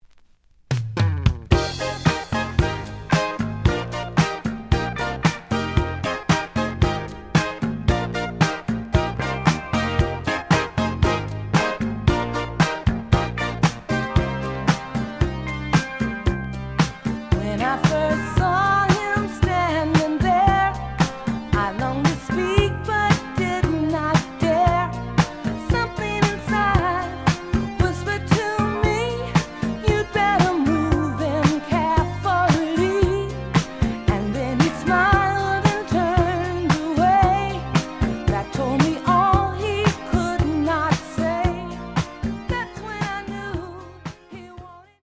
試聴は実際のレコードから録音しています。
The audio sample is recorded from the actual item.
●Format: 7 inch
●Genre: Disco